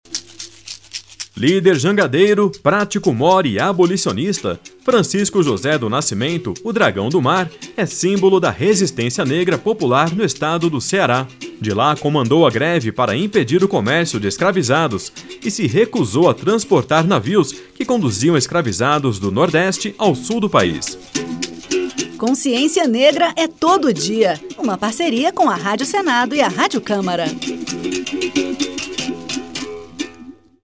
A Rádio Senado e a Rádio Câmara lançam uma série de cinco spots que homenageiam personalidades negras que marcaram a história do Brasil, como Abdias Nascimento, Carolina Maria de Jesus, Dragão do Mar, Maria Firmina dos Reis e Luiz Gama.